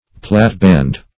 platband - definition of platband - synonyms, pronunciation, spelling from Free Dictionary
Platband \Plat"band`\, n. [F. plate-bande; plat, plate, flat,